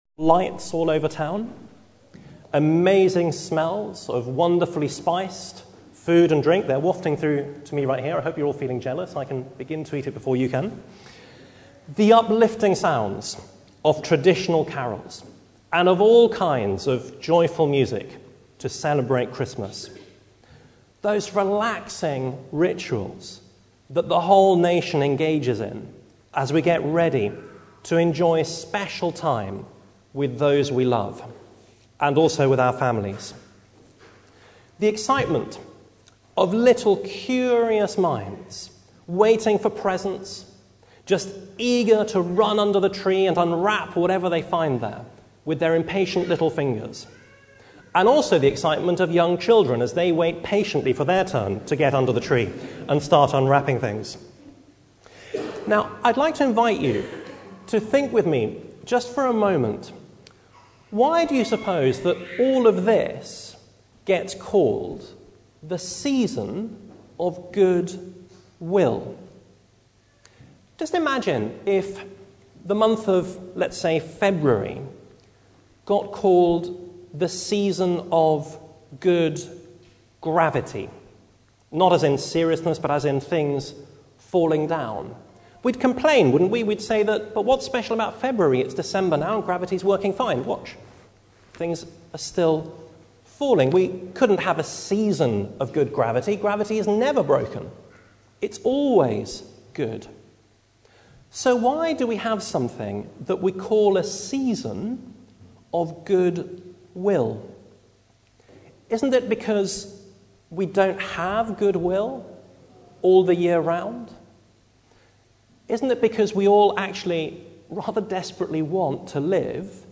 Carol service